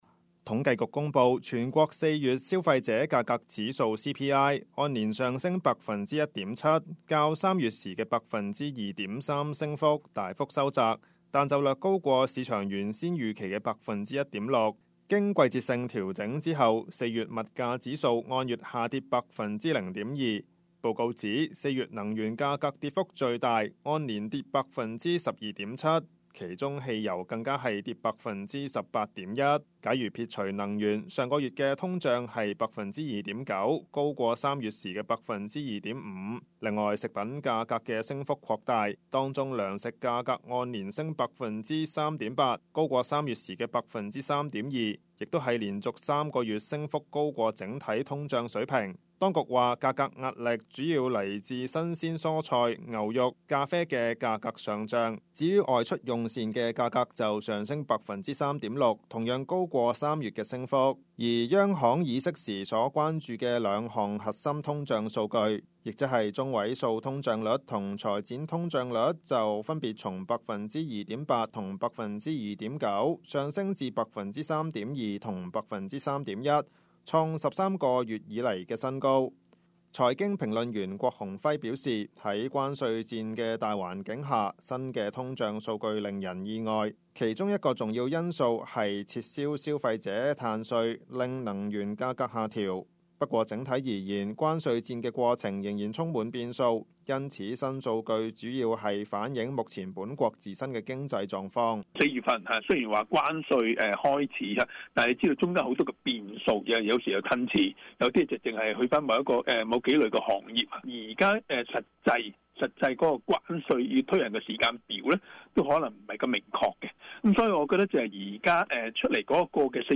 news_clip_23484.mp3